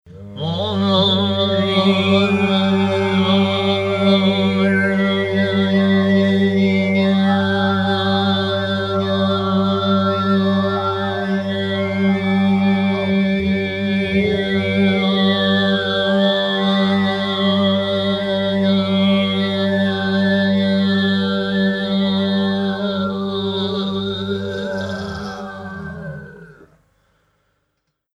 Il canto degli Armonici o Difonico.
MP3 (canto armonico due voci, sovrainciso)